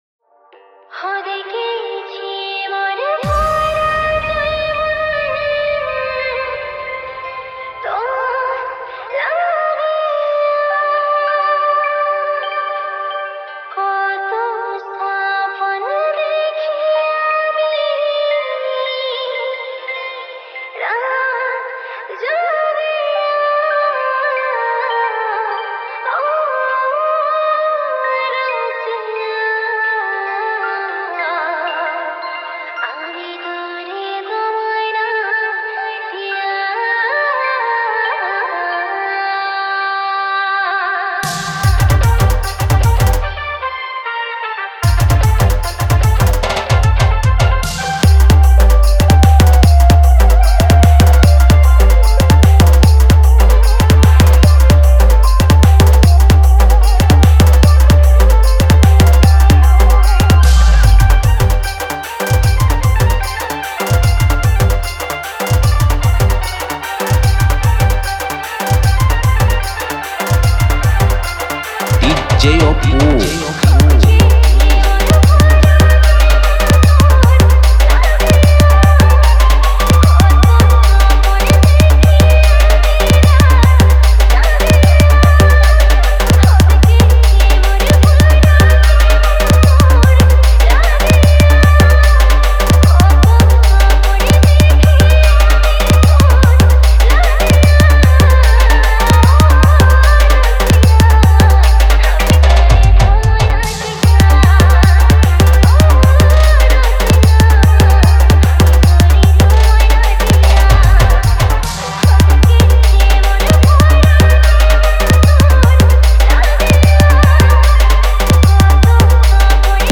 Purulia Roadshow Matal Dance Mix